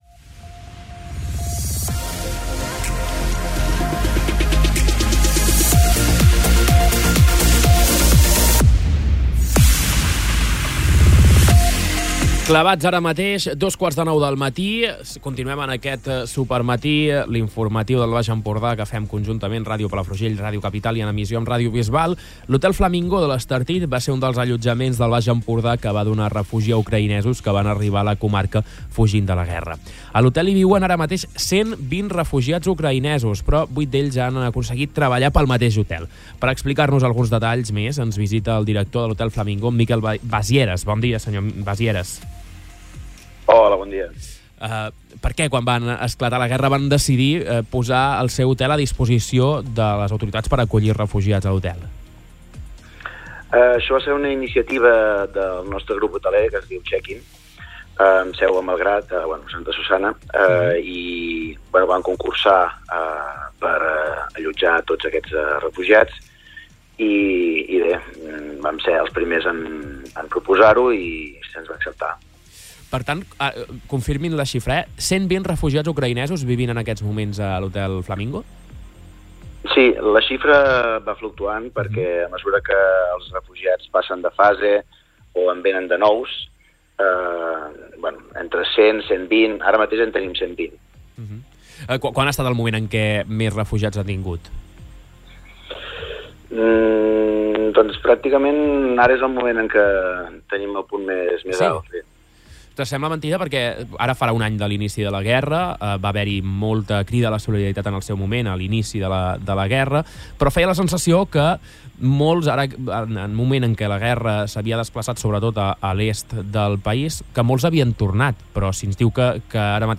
Entrevista-Flamingo.mp3